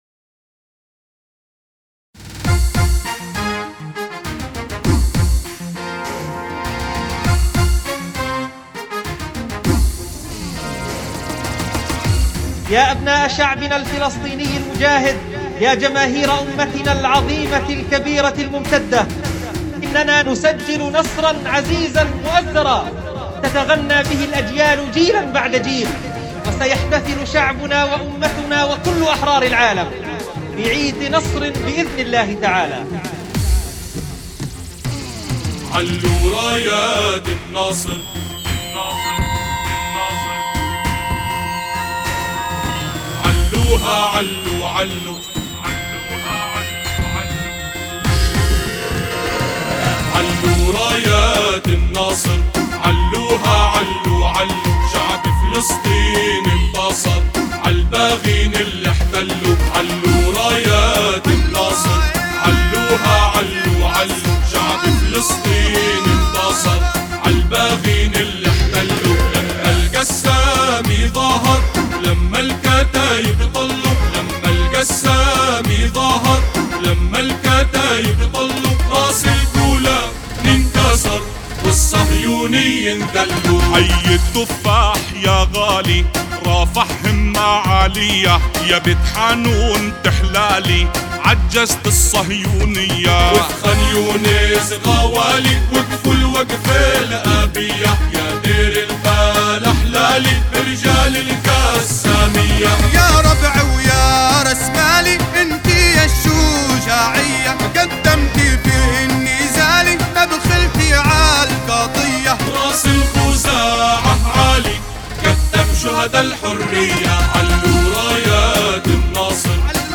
أناشيد فلسطينية... رايات النصر